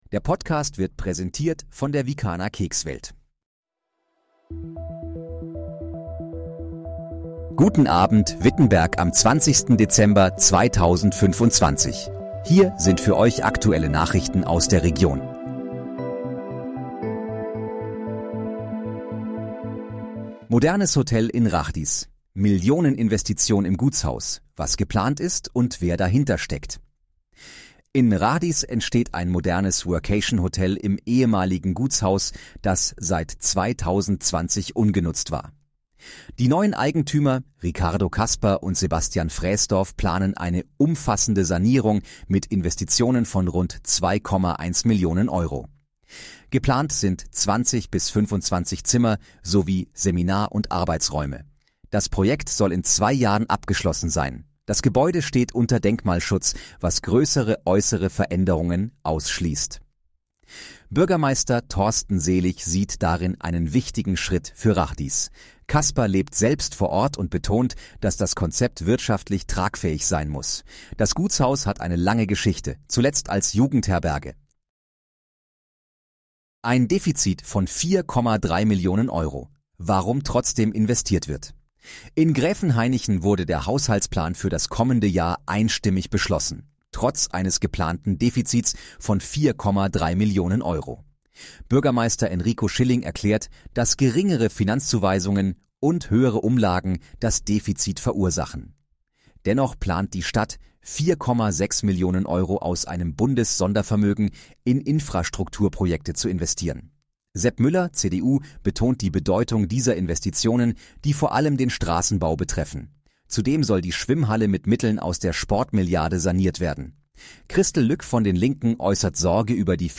Guten Abend, Wittenberg: Aktuelle Nachrichten vom 20.12.2025, erstellt mit KI-Unterstützung